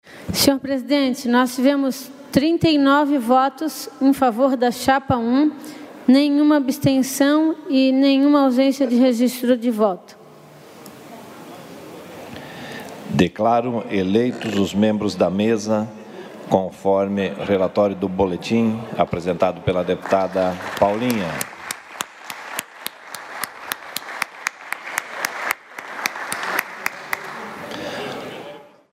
Confira os pronunciamentos dos deputados durante a sessão preparatória desta segunda-feira (1º) para a eleição do presidente e da Mesa Diretora da Assembleia Legislativa para o biênio 2021-2023.